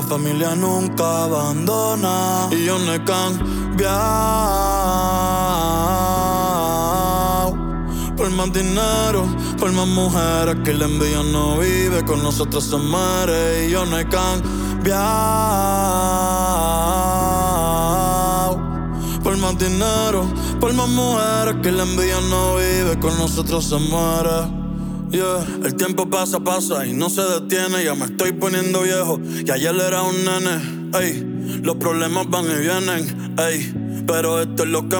Жанр: Латиноамериканская музыка / Русские